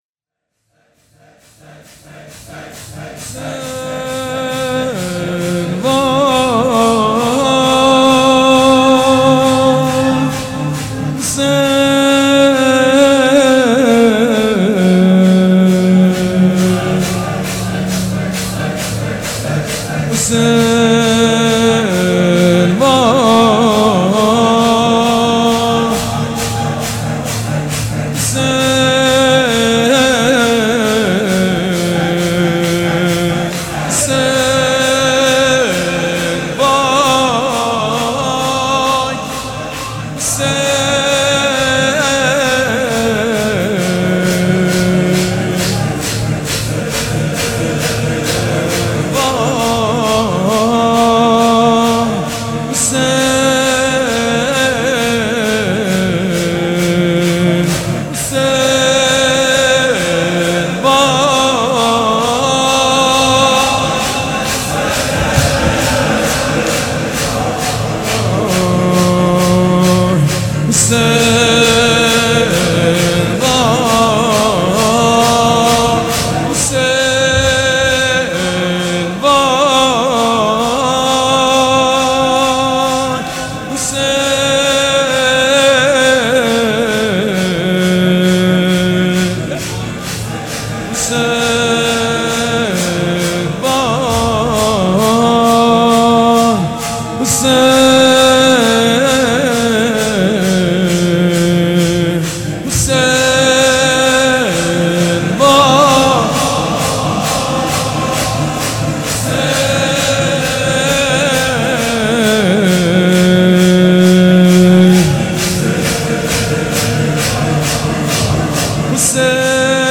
حاج سید مجید بنی فاطمه جمعه 16 شهریور 1397 هیئت ریحانه الحسین سلام الله علیها
سبک اثــر شور مداح حاج سید مجید بنی فاطمه
شور.mp3